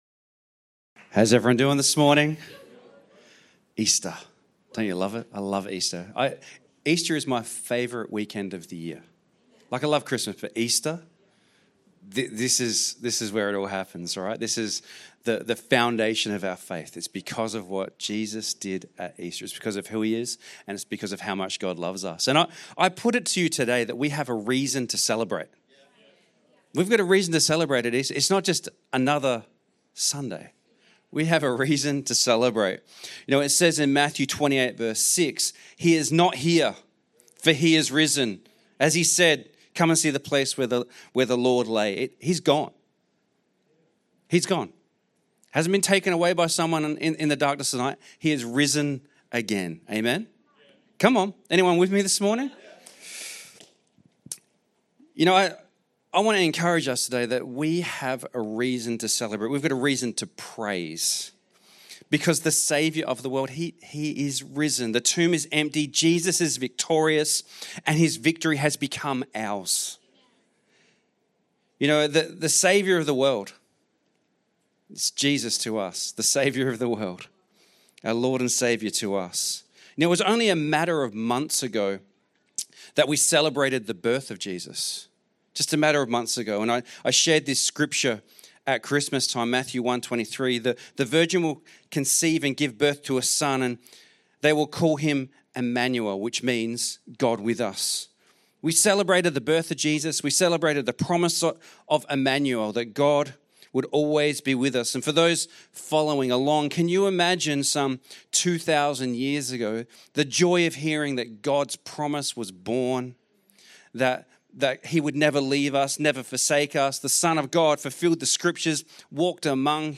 Sermons recorded at Gateway Church Geelong